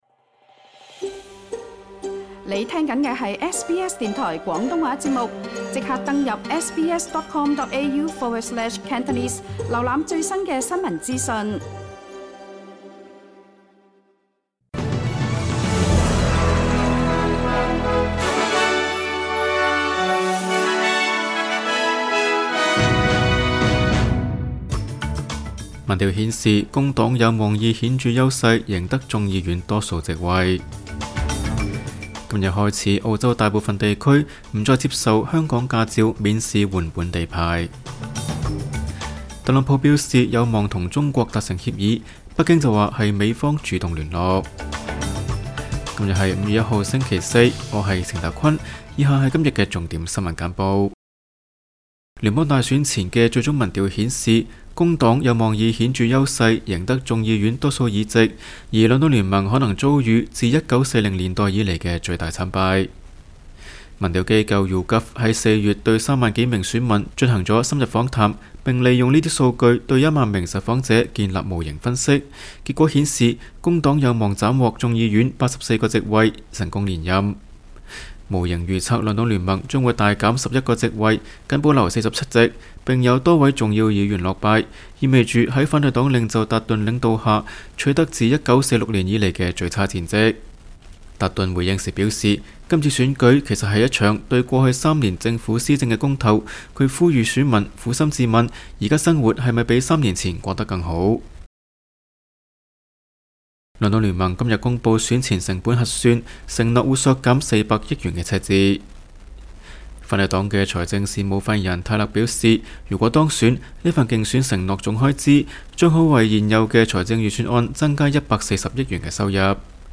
SBS晚間新聞（2025年5月1日） Play 05:54 SBS 廣東話晚間新聞 SBS廣東話節目 View Podcast Series 下載 SBS Audio 應用程式 其他收聽方法 Apple Podcasts  YouTube  Spotify  Download (5.41MB)  請收聽本台為大家準備的每日重點新聞簡報。